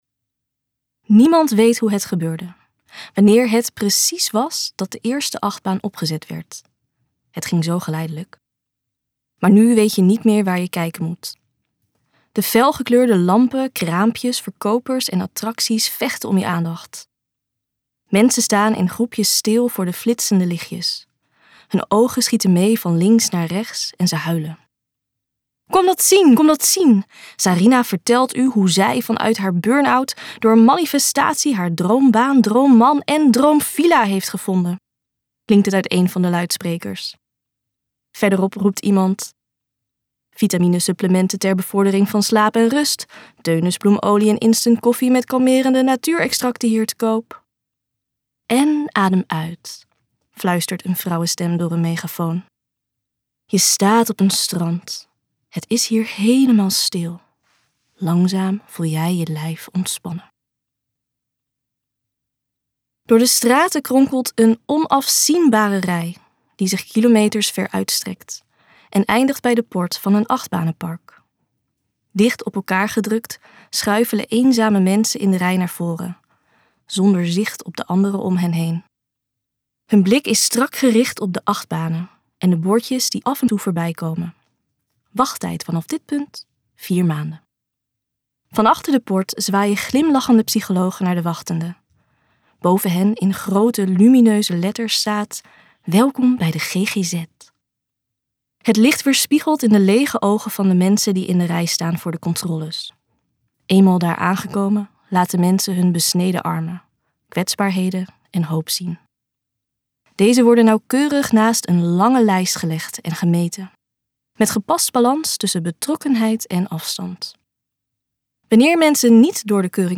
KokBoekencentrum | Nederland therapieland luisterboek